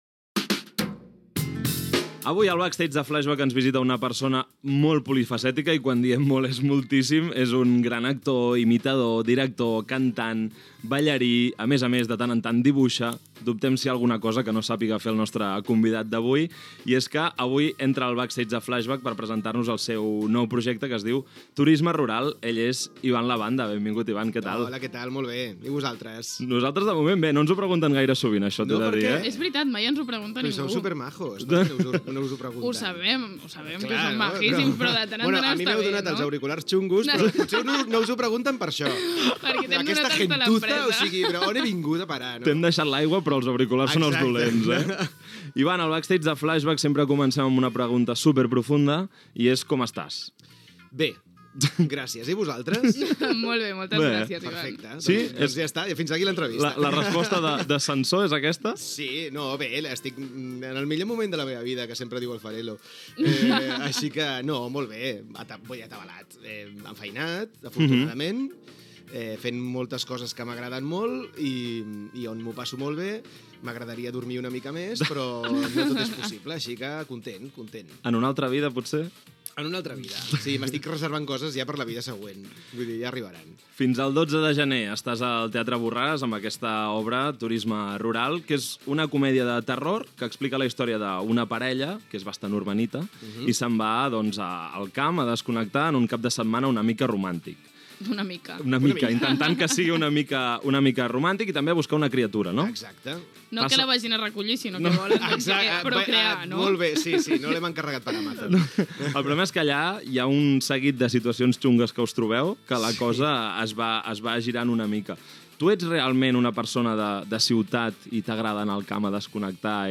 Presentació i entrevista a l'actor Ivan Labanda sobre l'obra "Turisme rural"
Entreteniment